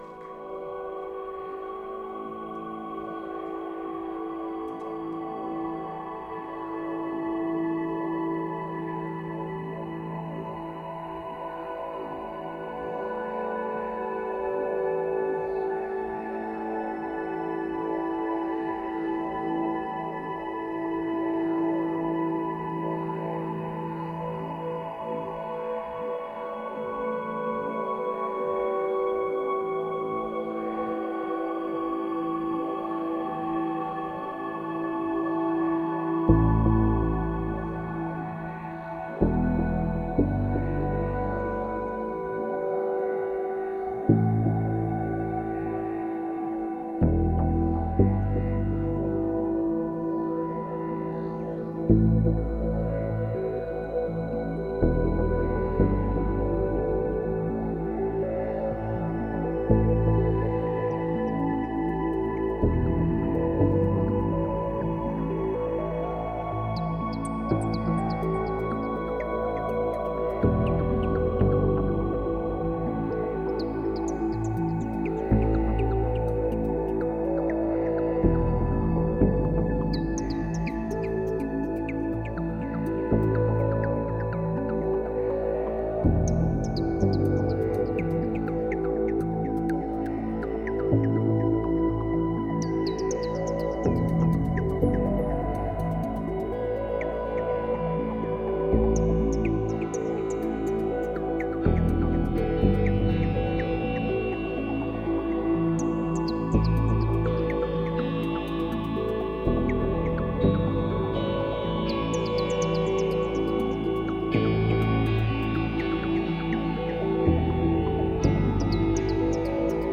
Groundbreaking ambient and dark-ambient.